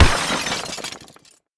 Download Crash sound effect for free.
Crash